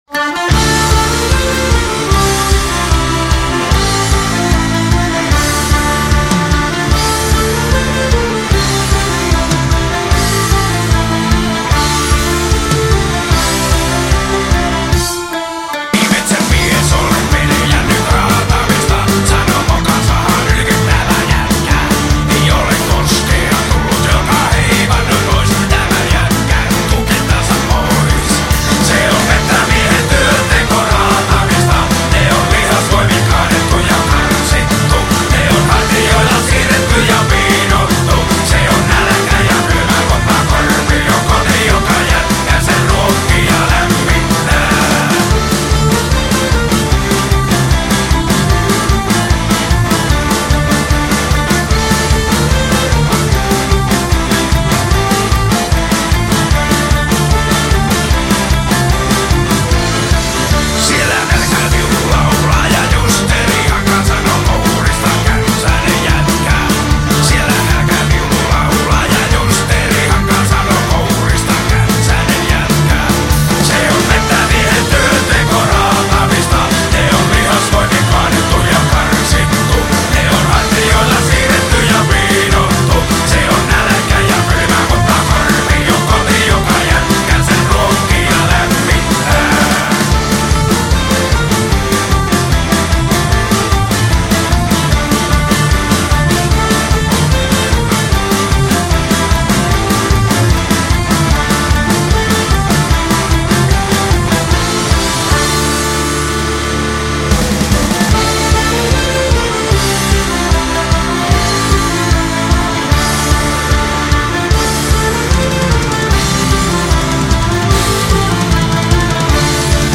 风格：哥特金属, 硬摇滚, 金属